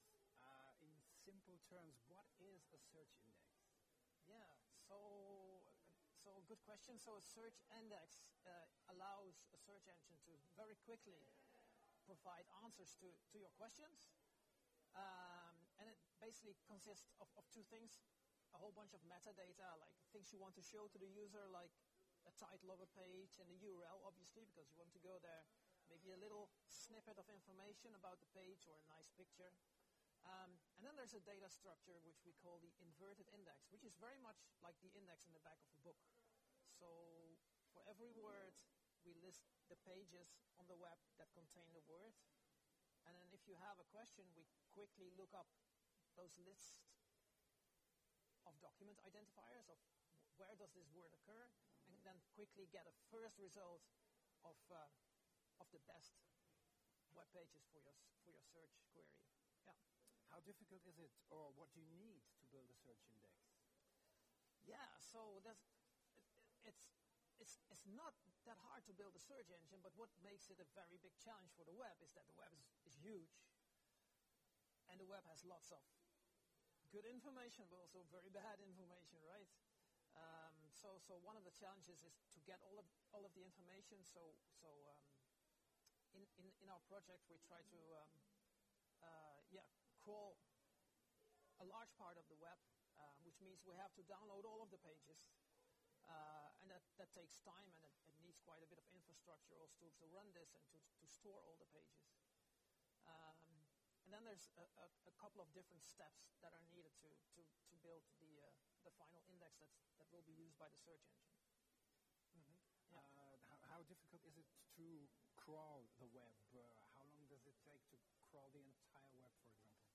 Listen to the audio of the full interview here: